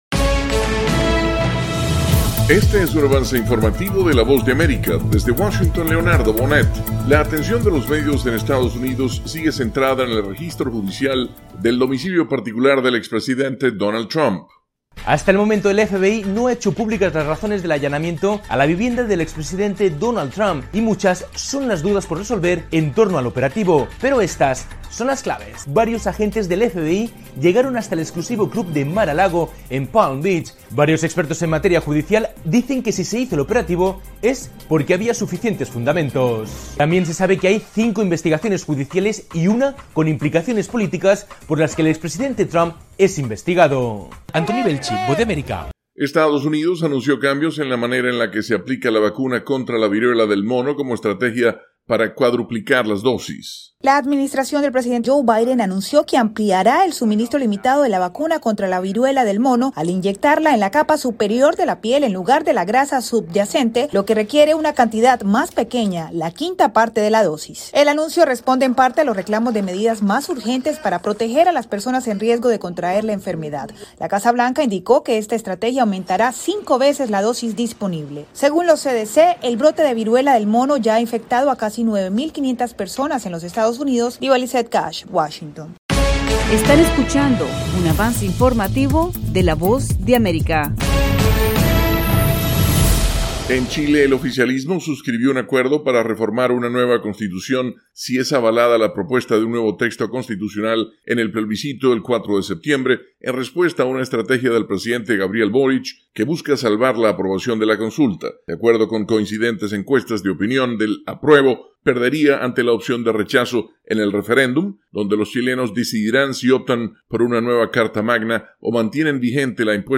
Avance Informativo - 4:00 PM
El siguiente es un avance informativo presentado por la Voz de América, desde Washington